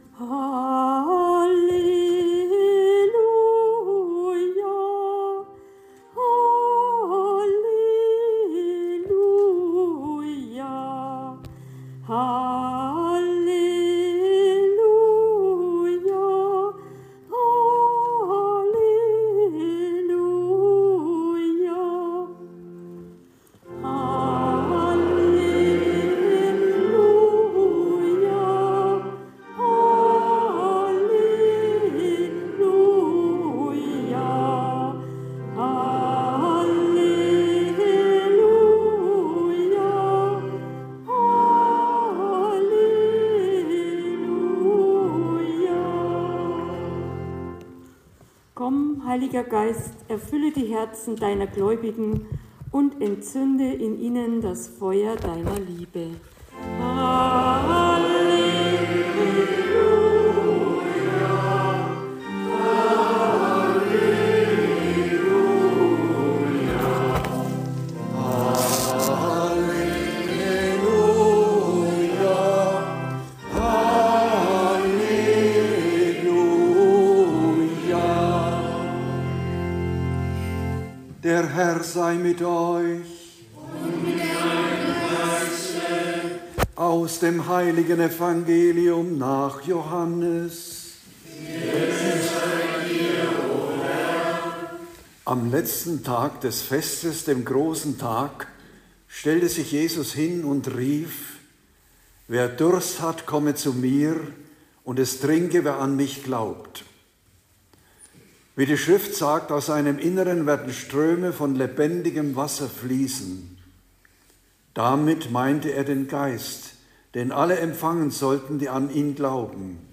Pfingstpredigt